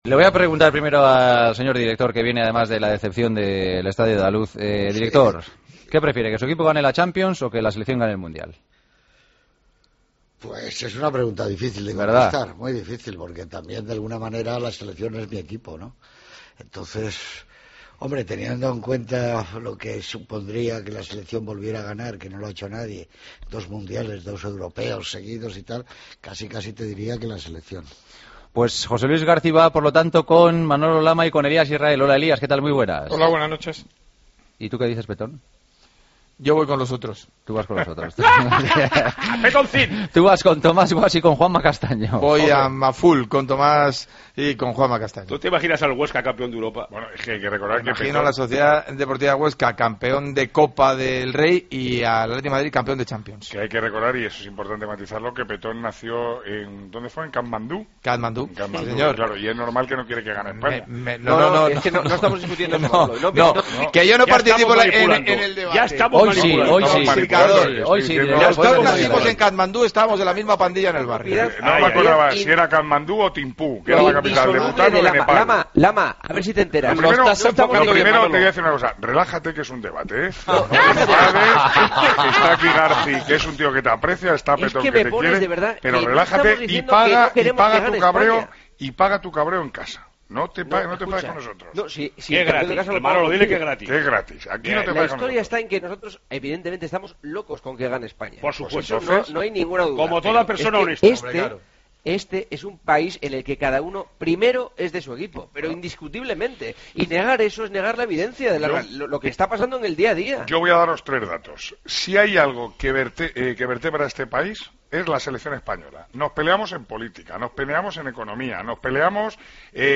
El debate de los jueves: ¿Qué prefieres: que tu equipo gane la Champions o que España gane el Mundial?